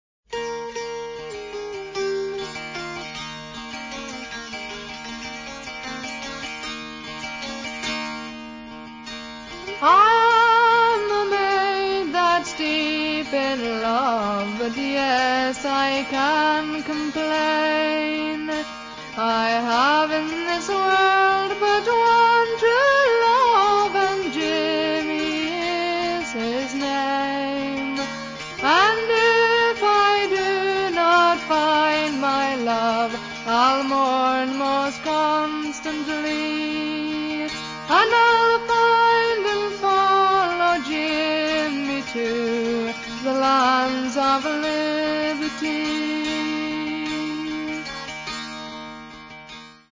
First part, 0:50 sec, mono, 22 Khz, file size: 195 Kb.